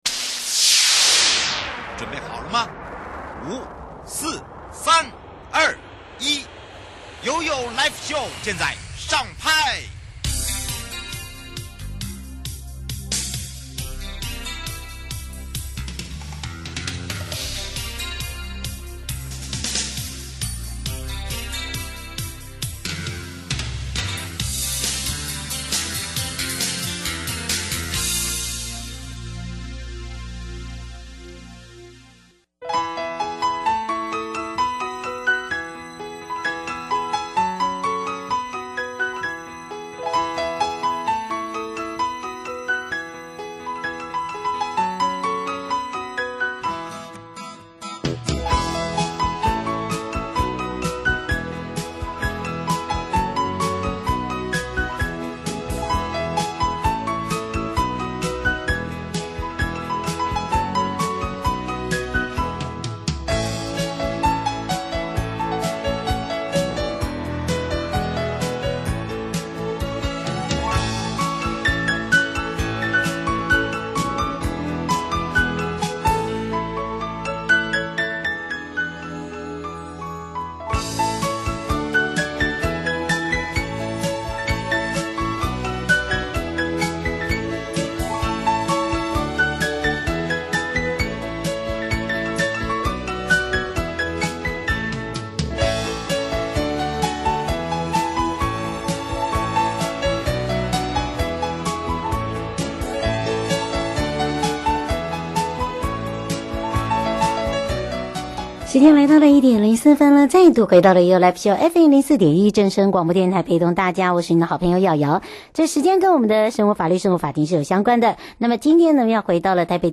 受訪者： 台北地檢 黃珮瑜主任檢察官 節目內容： 1. 具感染風險民眾趴趴走之處罰基準?什麼樣的人要居家檢疫?